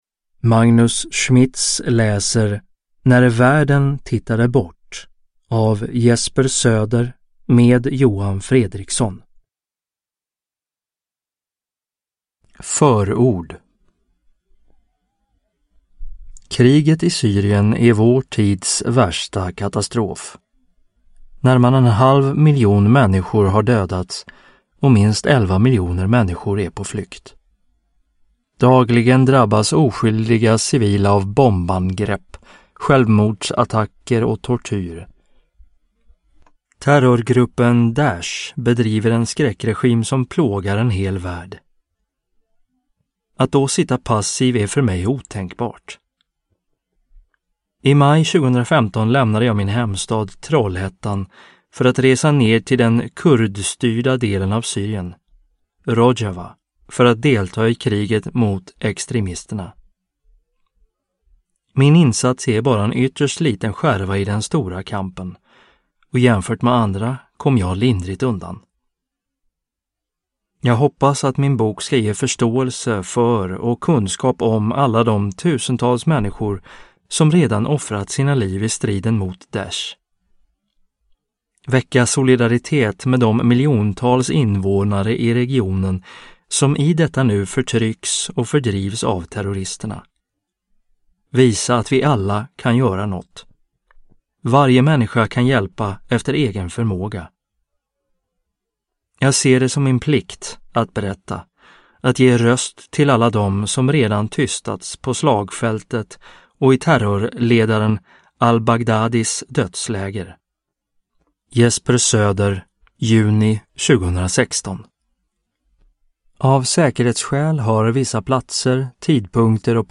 Uppläsare:
Ljudbok